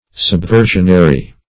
Subversionary \Sub*ver"sion*a*ry\, a.